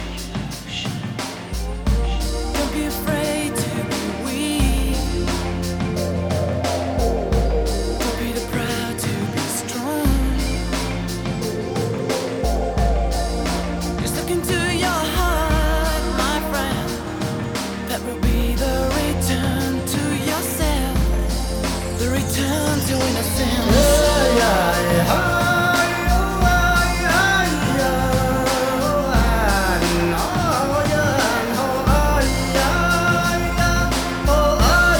Жанр: Нью-эйдж